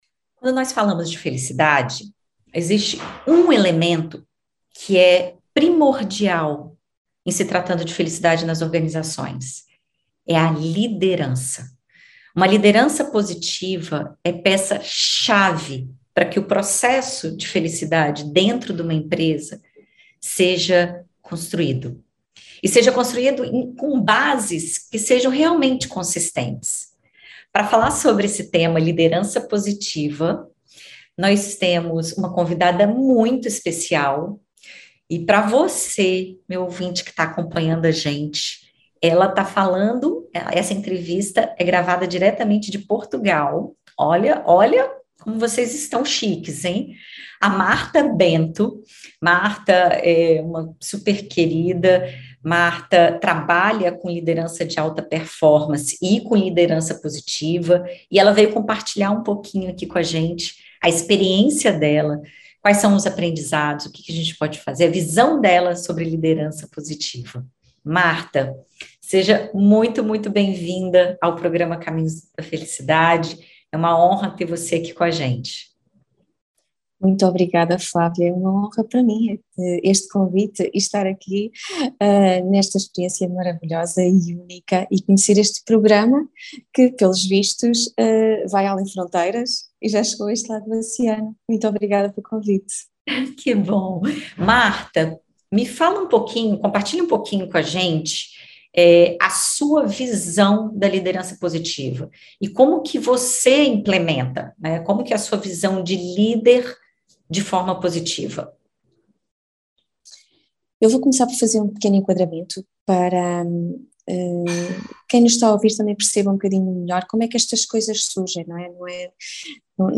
Na BandNews FM